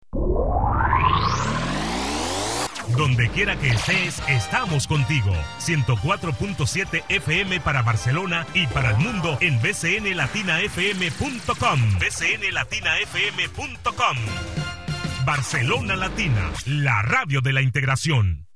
Identificació de l'emissora i adreça del portal web a Internet
Banda FM